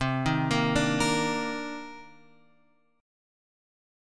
C Minor 9th